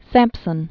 (sămpsən), Deborah 1760-1827.